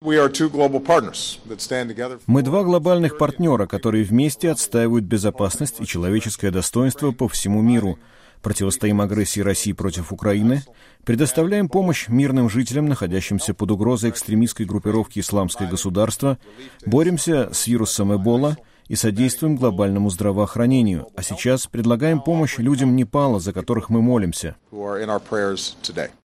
Президент США Барак Обама выступает на совместной пресс-конференции с премьер-министром Японии Синдзо Абе в Розовом саду Белого дома в Вашингтоне 28 апреля 2015 года